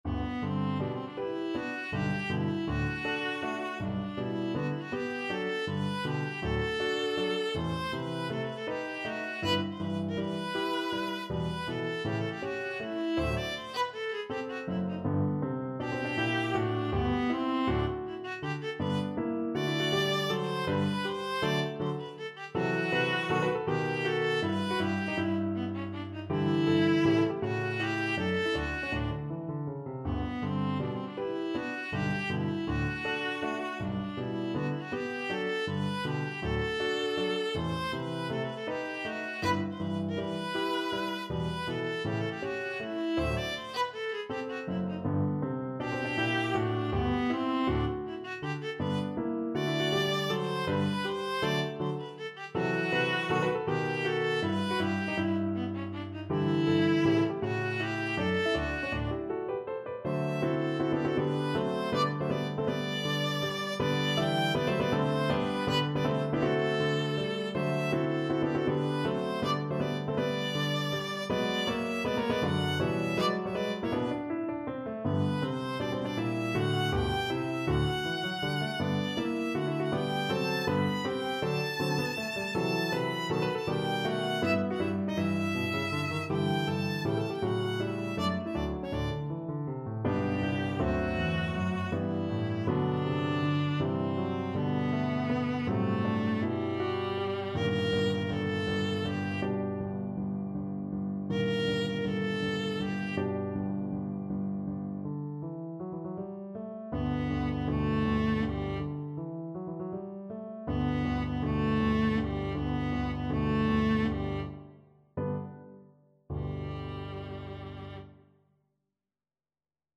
5/4 (View more 5/4 Music)
G4-B6
Classical (View more Classical Viola Music)